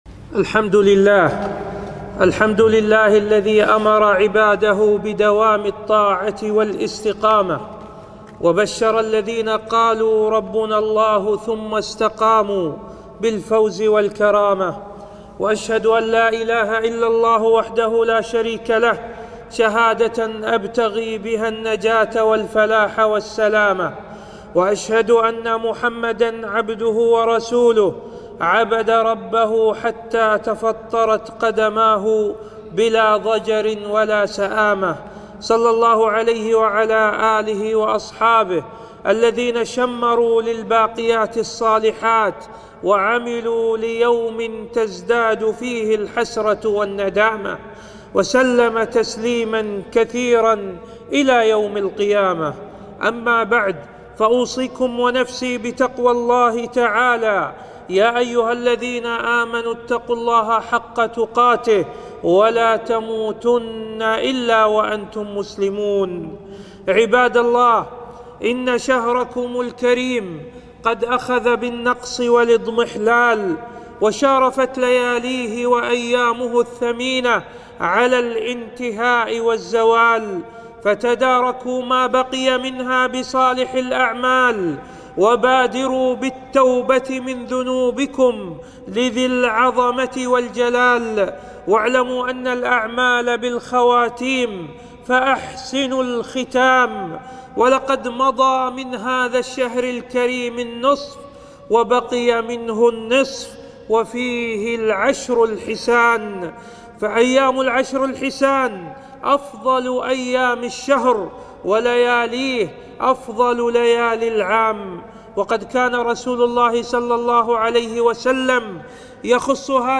خطبة - فضل العشر الأواخر من رمضان - وجوب الزكاة